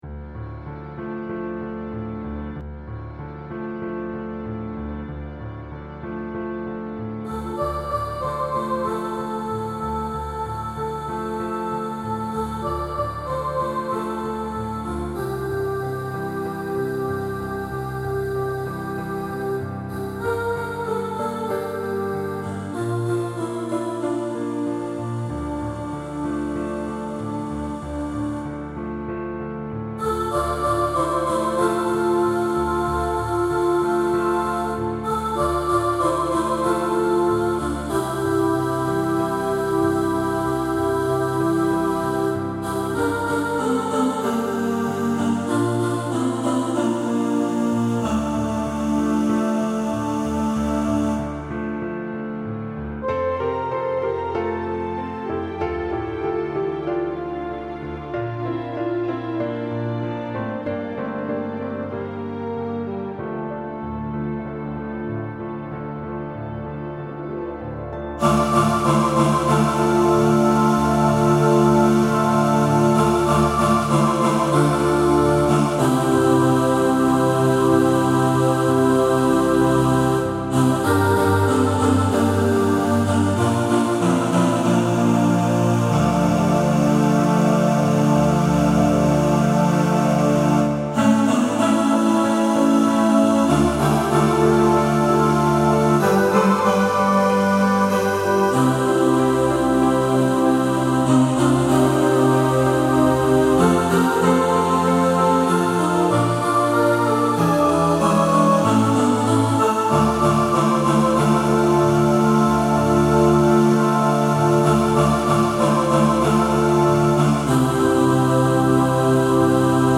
Walking-In-The-Air-All-Voices.mp3